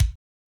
MOO Kick 1.wav